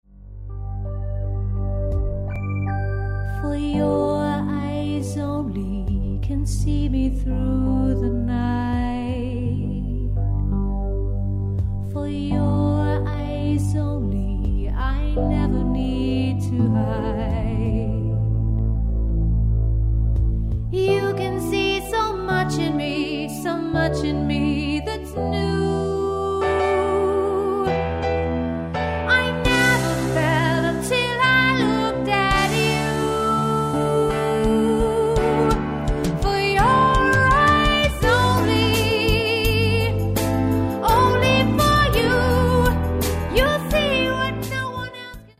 • Solo Singer